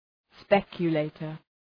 Shkrimi fonetik{‘spekjə,leıtər}